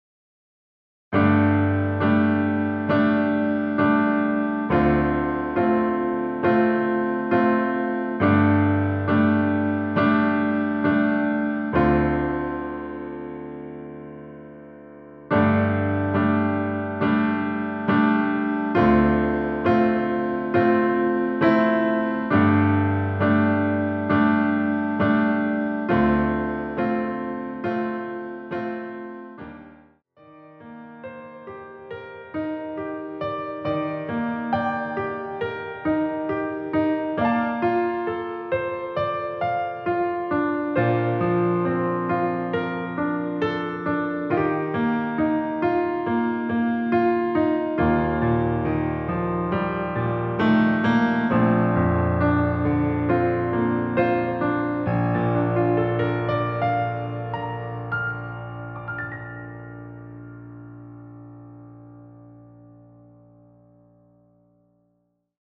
엔딩이 너무 길고 페이드 아웃 이라 라이브 하시기 좋게 엔딩을 만들어 놓았습니다.(미리듣기 참조)
◈ 곡명 옆 (-1)은 반음 내림, (+1)은 반음 올림 입니다.
앞부분30초, 뒷부분30초씩 편집해서 올려 드리고 있습니다.